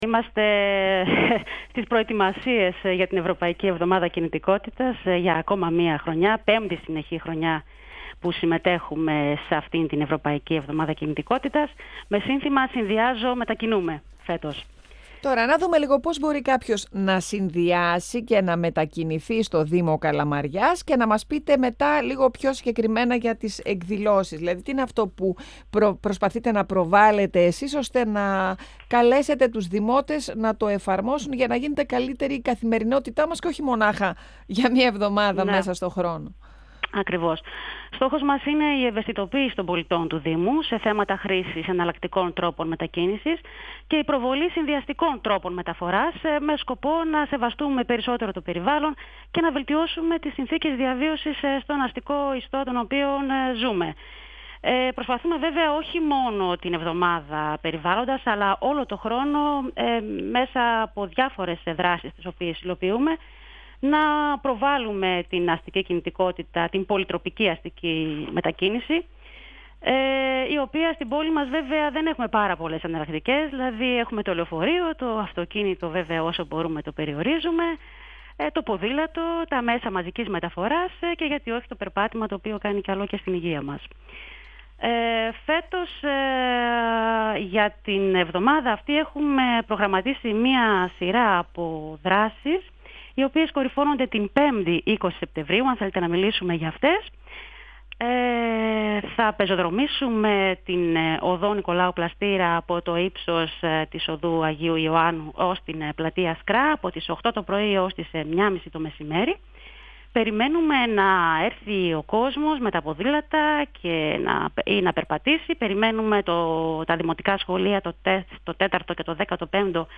μιλώντας στον 102FM του Ραδιοφωνικού Σταθμού Μακεδονίας της ΕΡΤ3